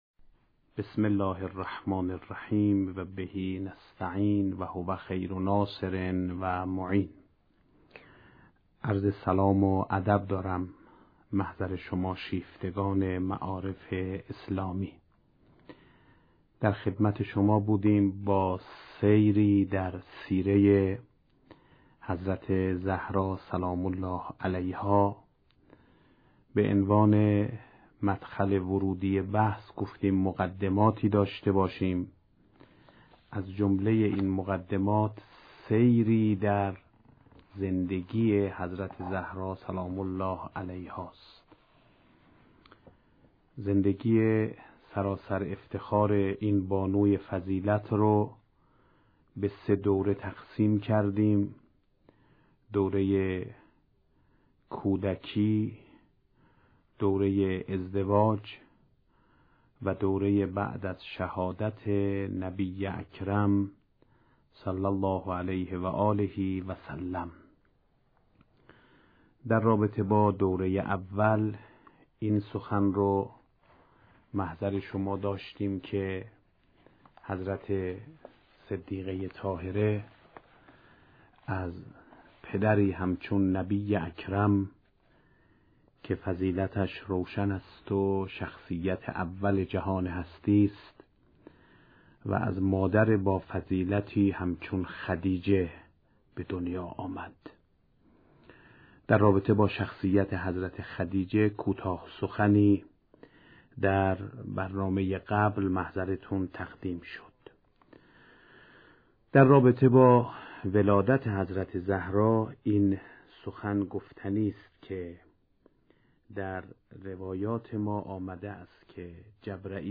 سخنرانی «آیت الله سید احمد خاتمی» پیرامون «سیره تربیتی حضرت زهرا(س)» با موضوع «ولادت حضرت زهرا(س)» (25:12)